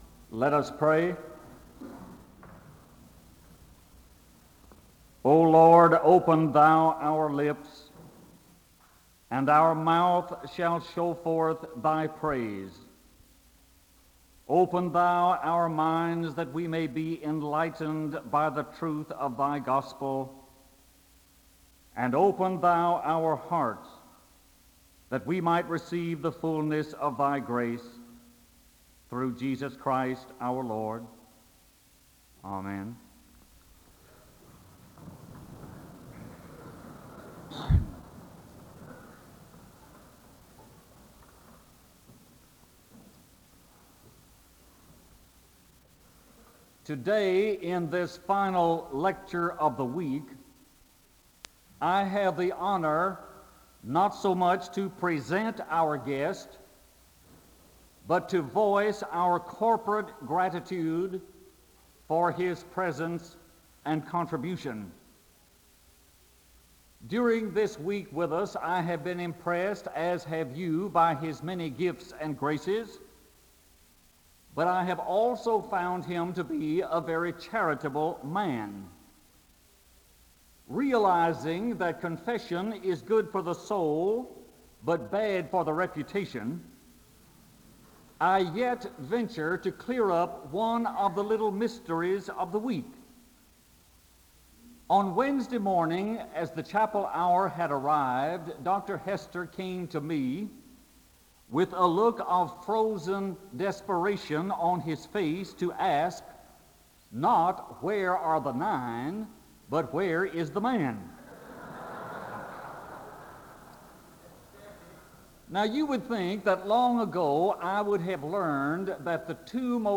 (audio is poor towards the beginning) Fred B. Craddock, Jr. was Bandy Distinguished Professor of Preaching at the Candler School of Theology at Emory University.
A word of prayer begins the service (0:00-0:34).
The choir sings (cut) (5:07-5:19).